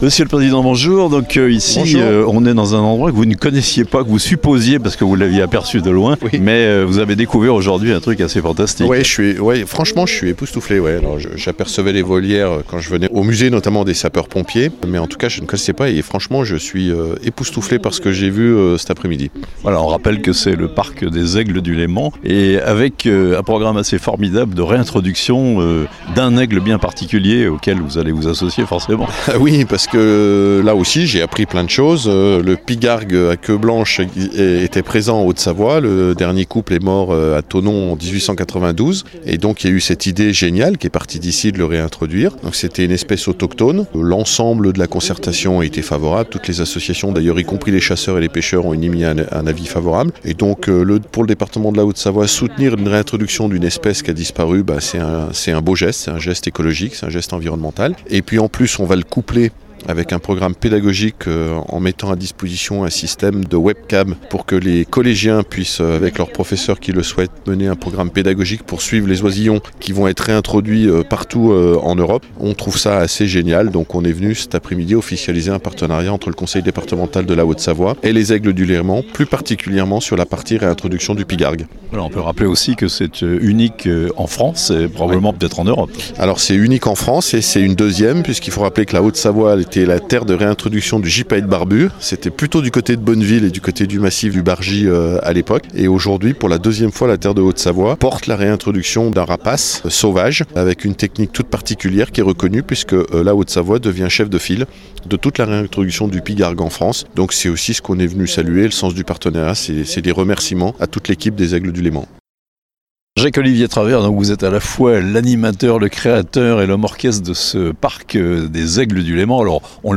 Le Département 74 s’engage pour la réintroduction du pygargue à queue blanche (interviews)